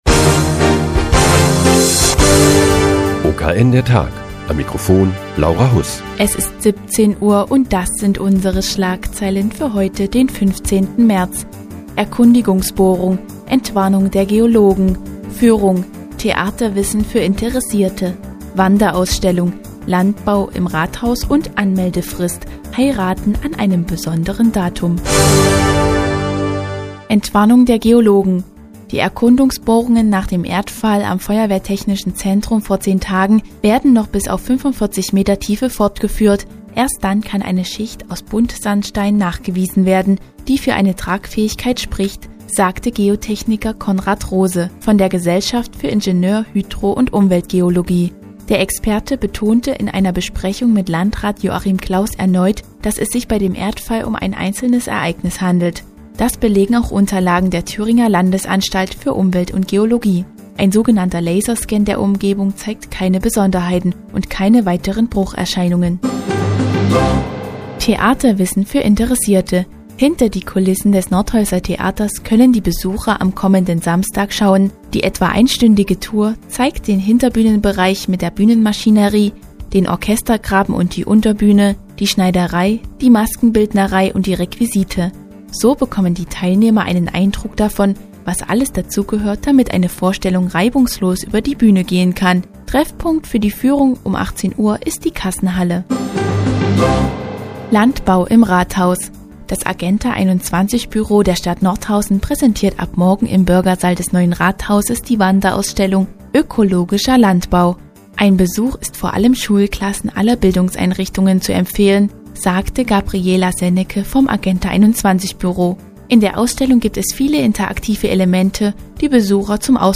Die tägliche Nachrichtensendung des OKN ist nun auch in der nnz zu hören. Heute geht es um eine Führung hinter die Kulissen des Nordhäuser Theaters und die Wanderausstellung "Ökologischer Landbau" im Rathaus.